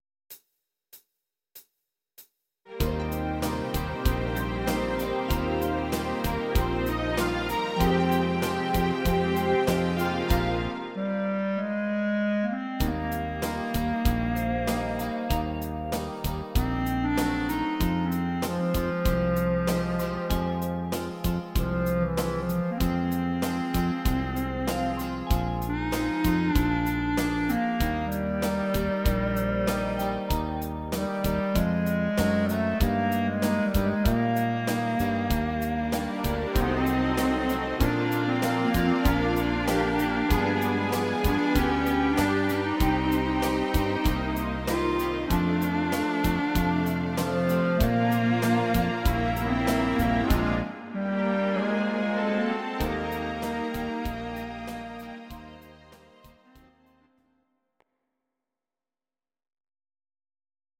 Please note: no vocals and no karaoke included.
instr. clarinet